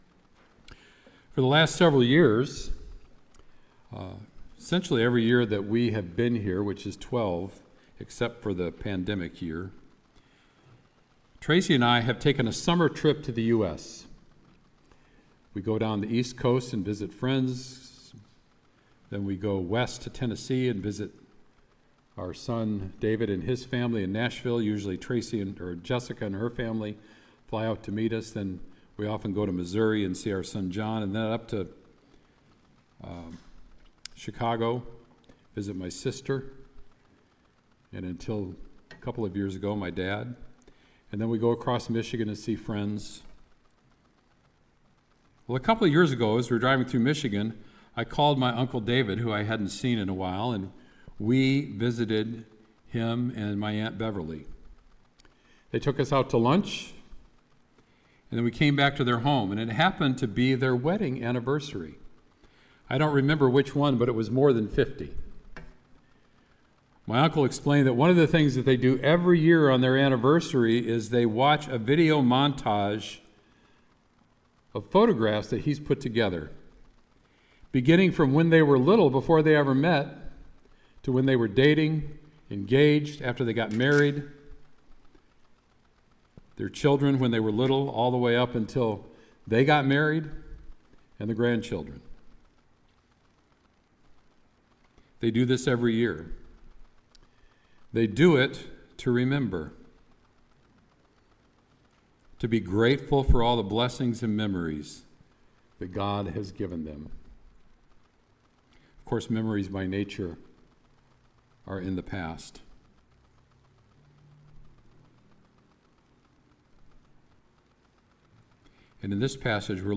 Sermon “Jesus Restores Peter”